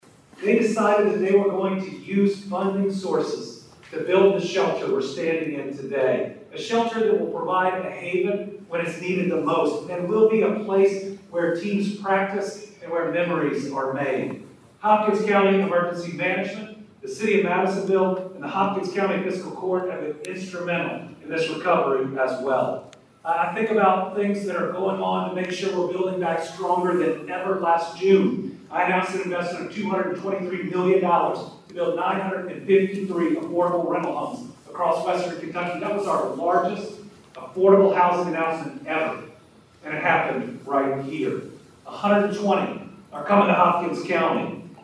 A moment of pride and resilience was on full display in Hopkins County as Governor Andy Beshear, school officials, and community members gathered to celebrate the opening of new auxiliary gyms and storm shelters at both local high schools Tuesday afternoon.